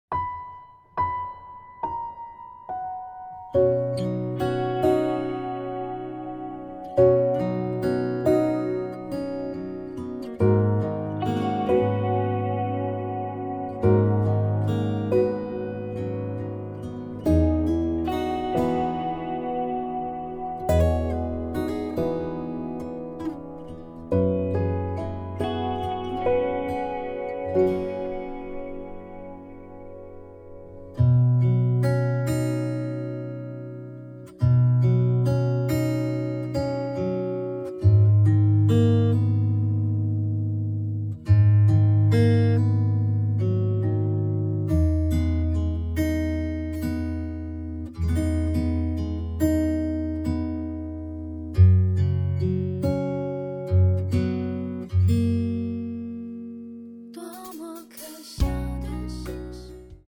歌曲调式：B大调